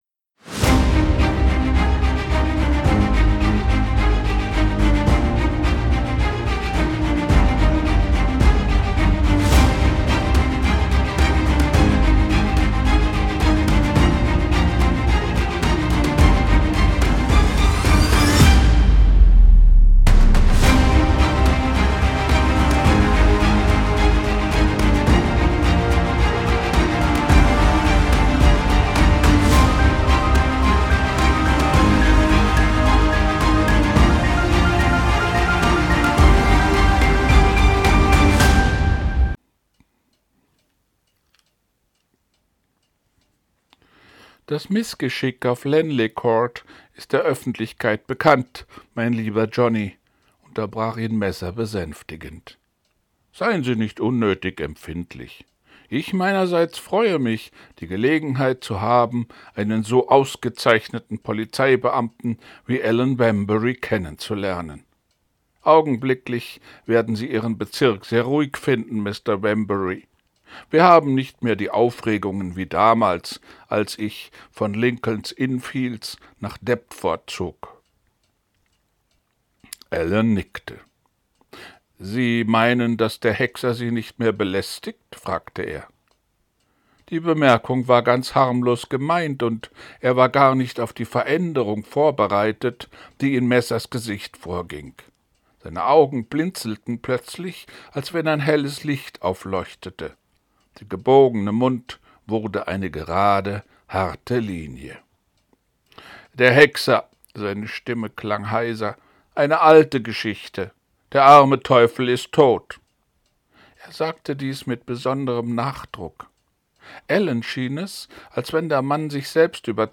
ich lese vor wallace hexer 3